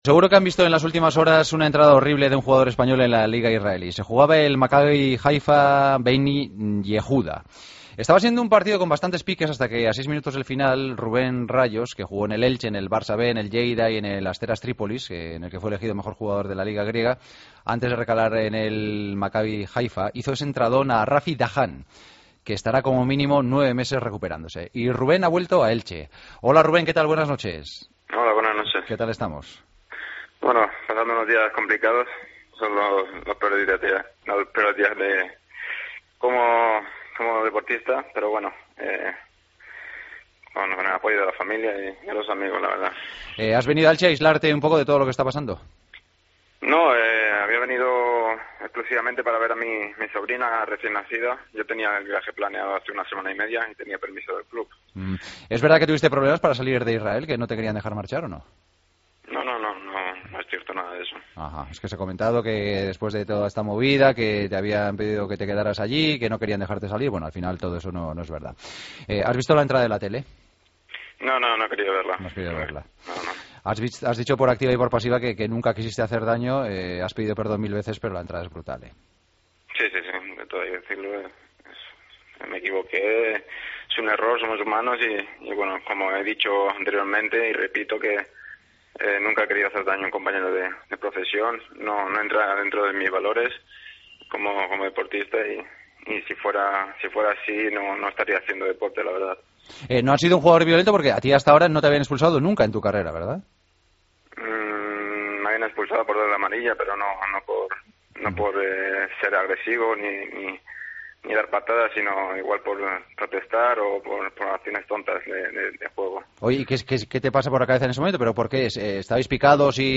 Hablamos con el futbolista español del Maccabi Haifa que lesionó de gravedad a un rival el pasado fin de semana con una escalofriante entrada a la rodilla: "Pasando días complicados como deportista.